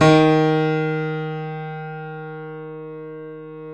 Index of /90_sSampleCDs/Roland L-CD701/KEY_YC7 Piano mf/KEY_mf YC7 Mono